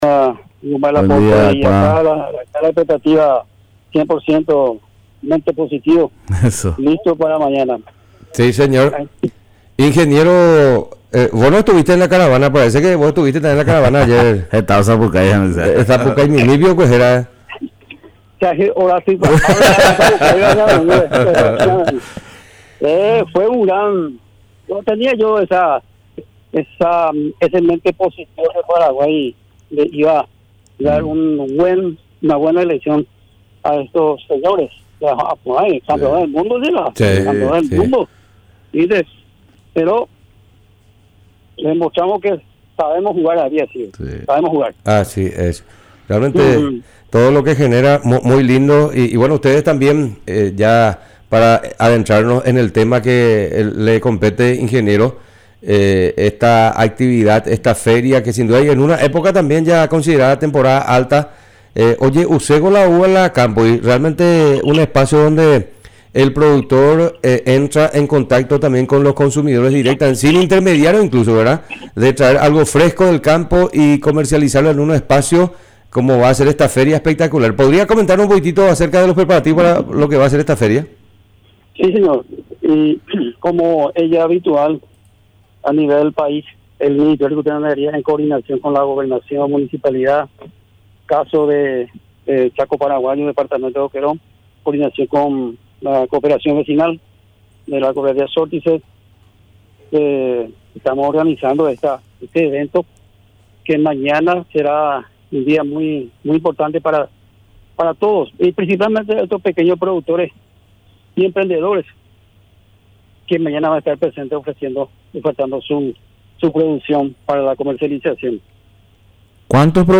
Entrevistas / Matinal 610 Feria de la agricultura familiar Nov 15 2024 | 00:12:35 1x 00:00 / 00:12:35 Subscribe Share RSS Feed Share Link Embed